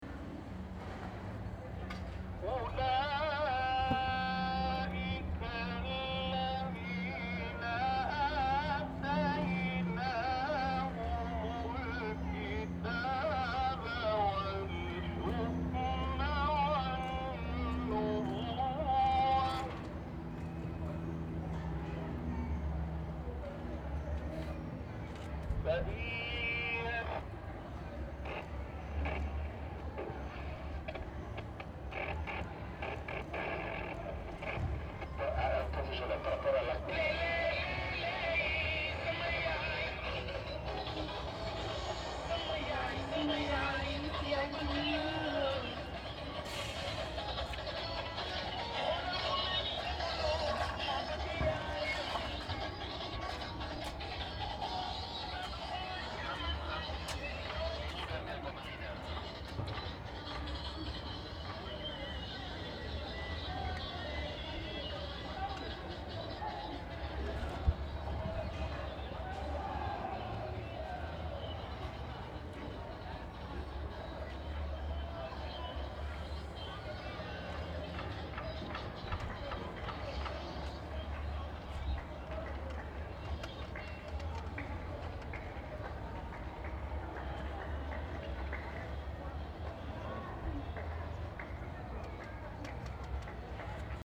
18_xxmedina_terrasse_court.mp3